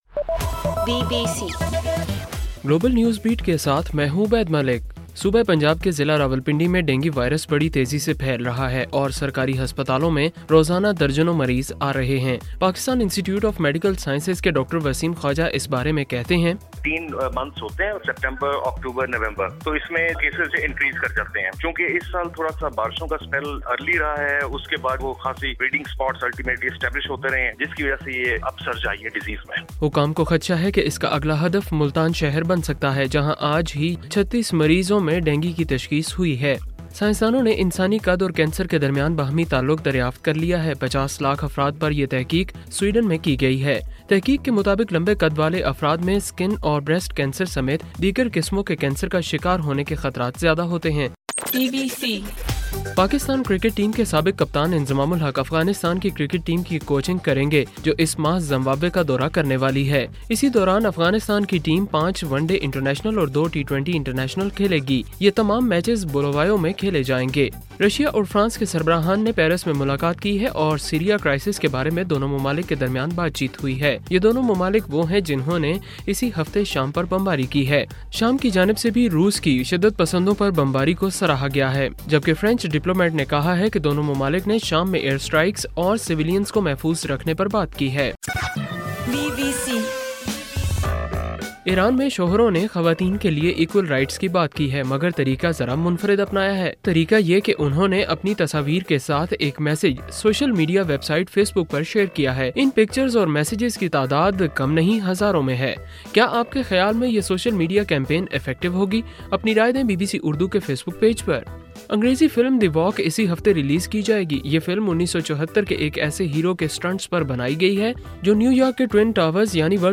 اکتوبر 2: رات 11 بجے کا گلوبل نیوز بیٹ بُلیٹن